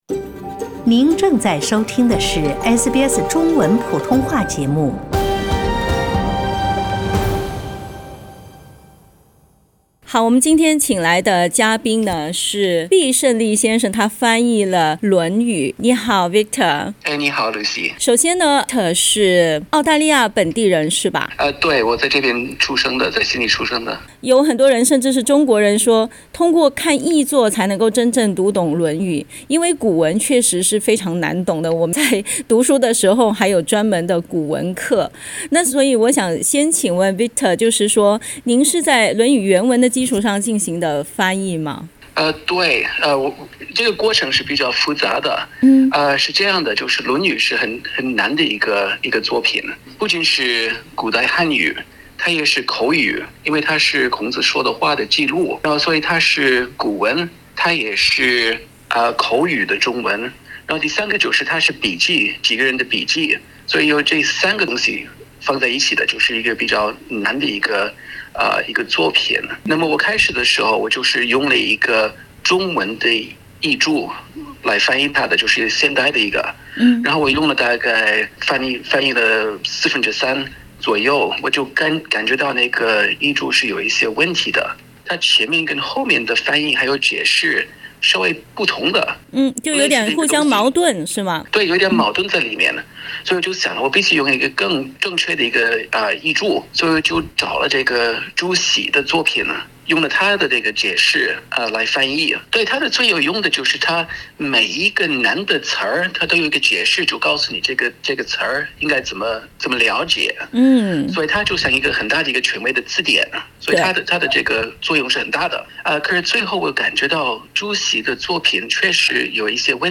（點擊圖片收聽完整寀訪）